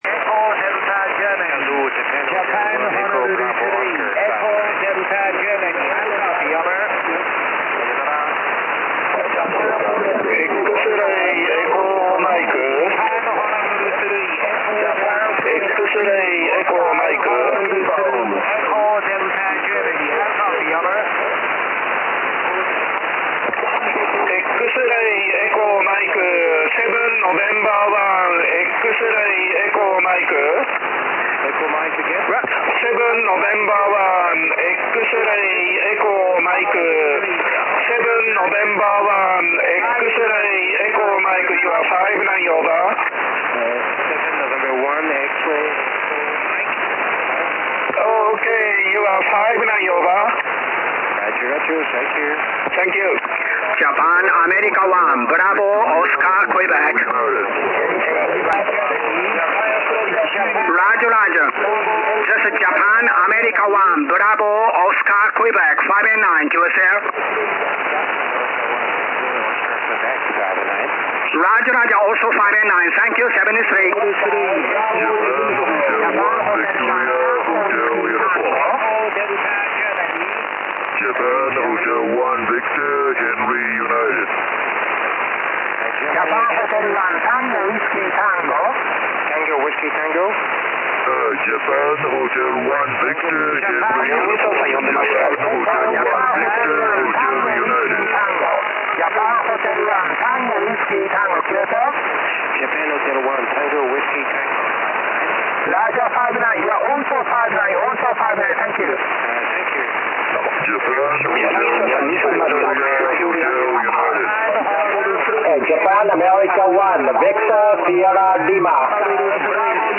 This is one of recordings of the pileup.